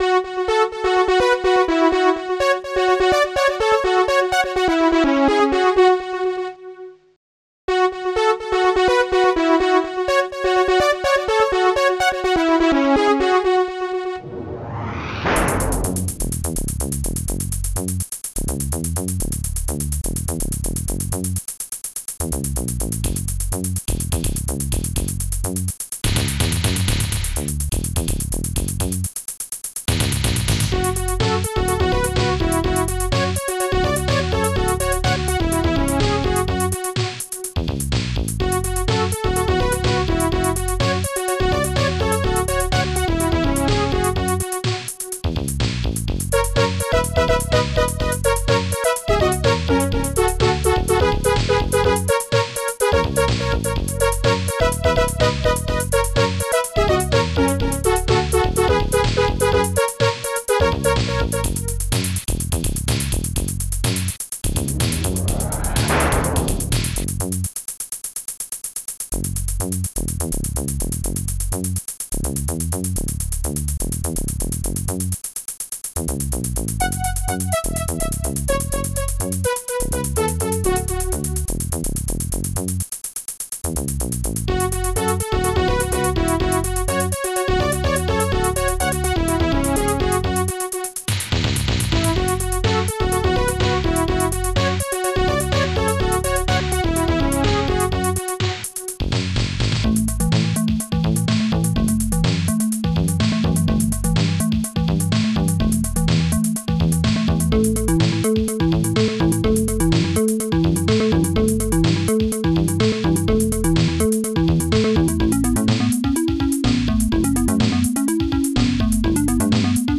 st-01:hallbrass
st-01:synbrass
st-01:popsnare2
st-01:bassdrum2
st-01:hihat2